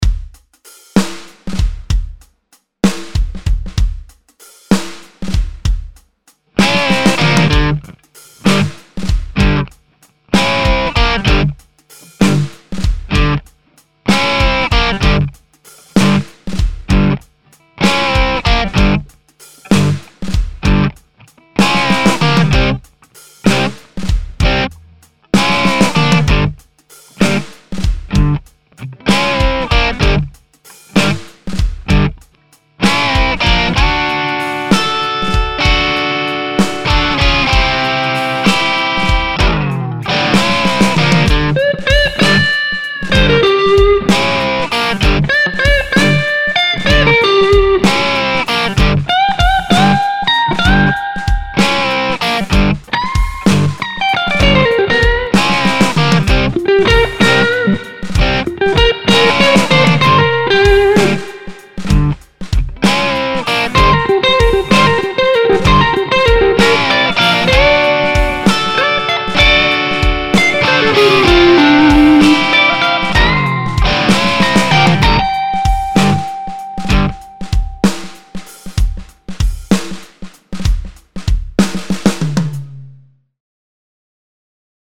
Pentatonic Blues with backing track, audio, and more.
Before the solo starts, the track plays through the rhythm part for several measures (tab included at the bottom of this page).
It works on bends and scale runs.
Solo